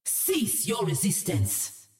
主动/敌方释放语音